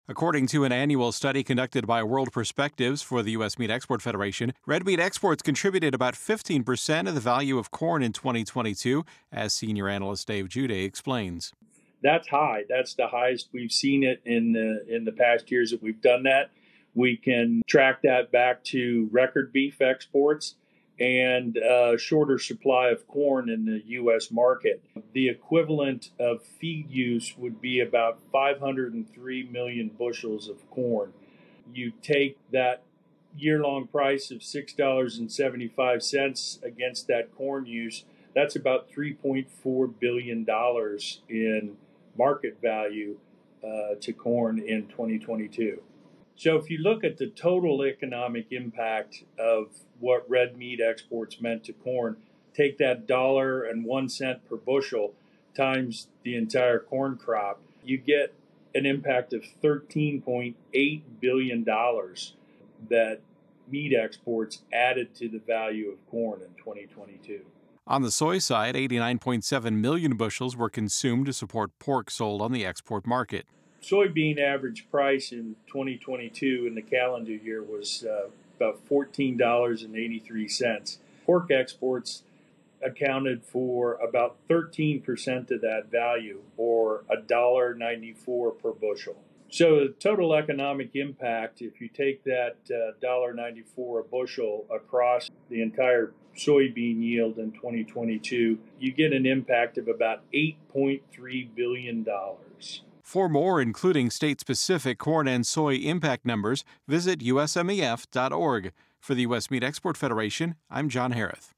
explains the numbers in this audio report and details the total economic impact of red meat exports for corn and soy growers in 2022.